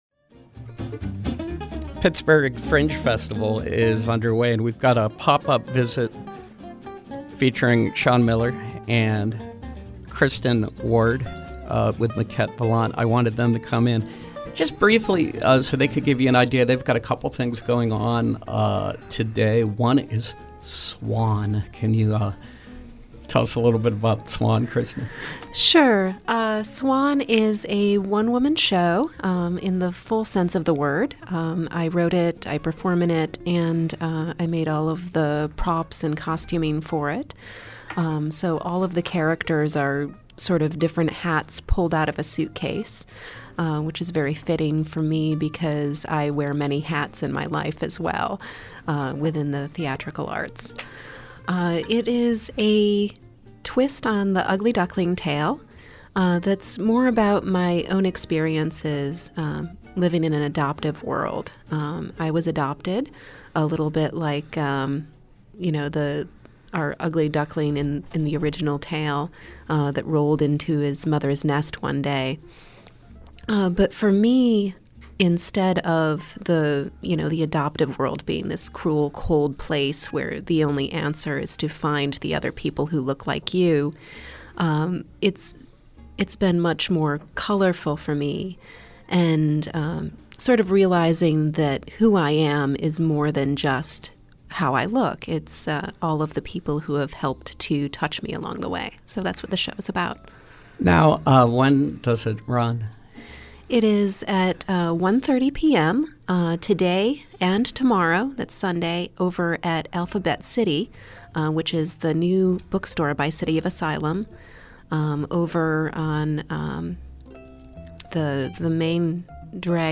Interview: Pittsburgh Fringe Festival